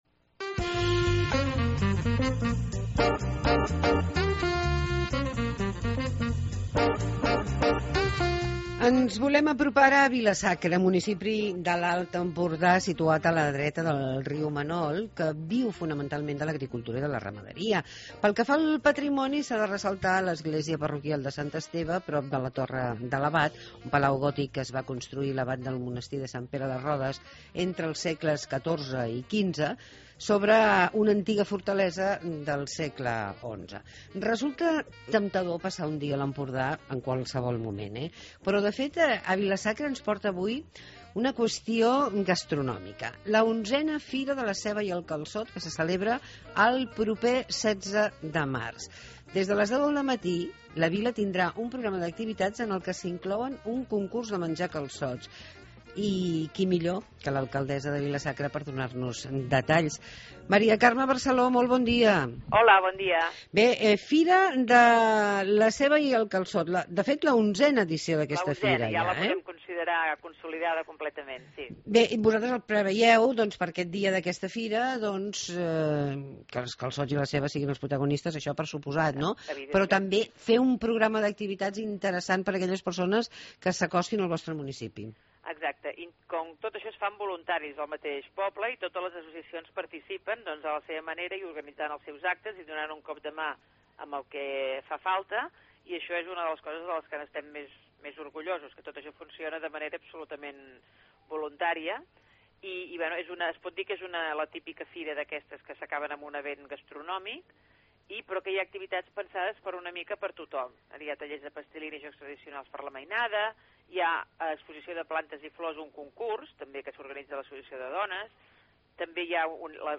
Demà es celebra la 11 Fira de la Ceba i el Calçot a Vilasacra parlem del que podrem fer amb l'alcaldessa, Mari Carme Barceló.